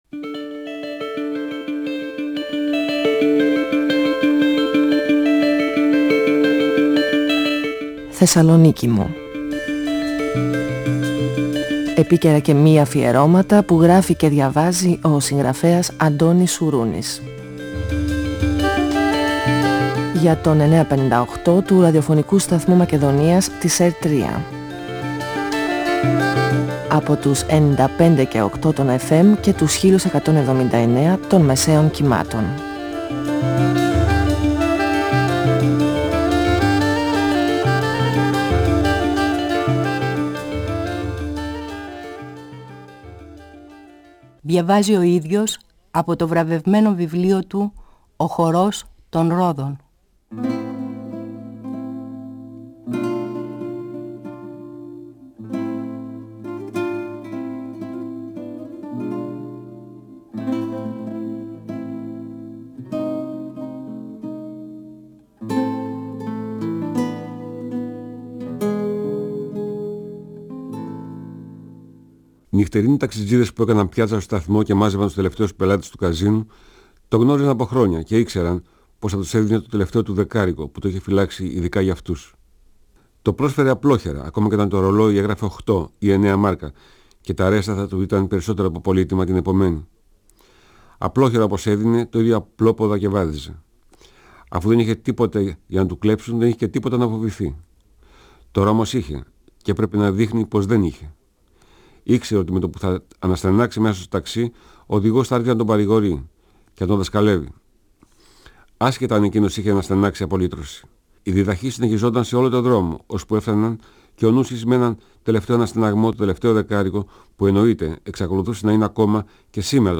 Ο συγγραφέας Αντώνης Σουρούνης (1942-2016) διαβάζει το πρώτο κεφάλαιο από το βιβλίο του «Ο χορός των ρόδων», εκδ. Καστανιώτη, 1994. Το Πουλί κερδίζει στο καζίνο. Ο Νούσης και ο Καθηγητής συναντούν τον Ρολφ στο Μπρέμεν και ελπίζουν να σταθούν τυχεροί στη ρουλέτα της περιοχής.